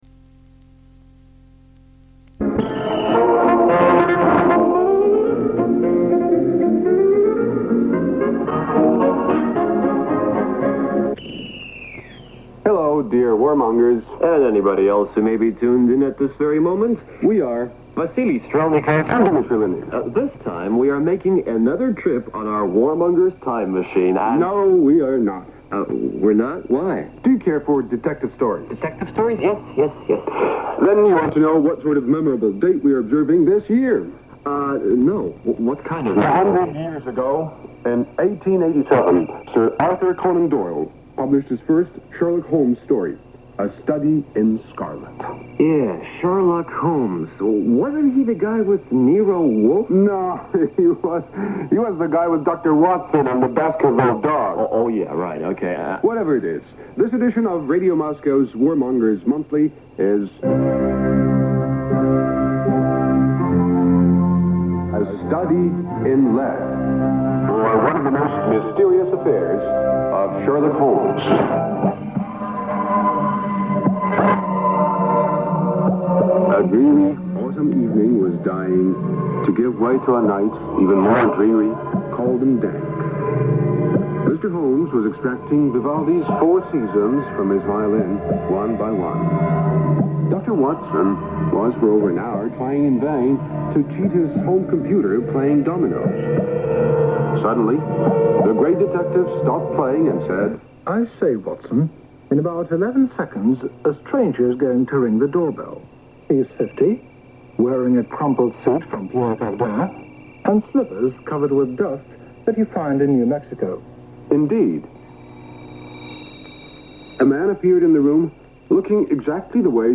Live, off-air, approximately 20-minute recording of the program "Warmongers' Monthly" transmitted as part of the daily evening show "Tonight" in the North American Service of Radio Moscow on 8 September 1987 around 01:20 UTC on 7400 kHz.
The broadcast originated from one of the many Radio Moscow transmission sites in the Soviet Union or was relayed by a site in eastern Europe or Cuba.
A brief station identification follows the program and then the transmitter abruptly switches off.